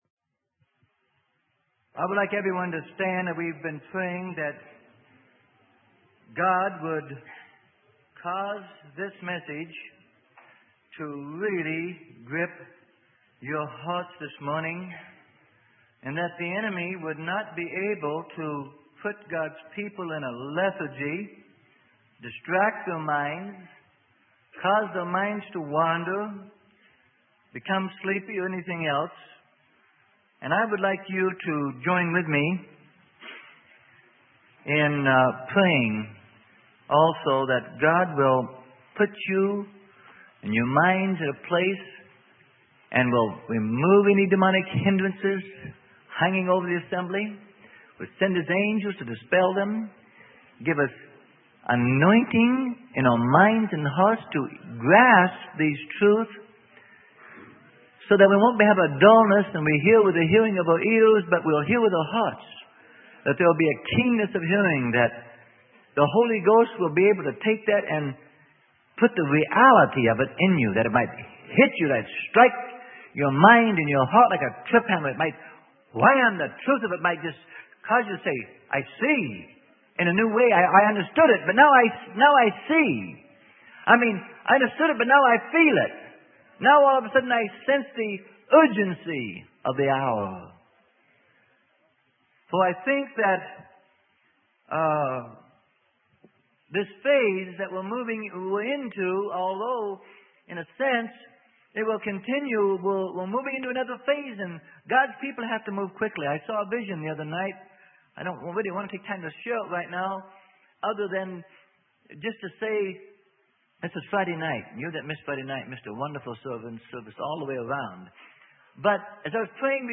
Sermon: The Spirit World - Part 2 - Freely Given Online Library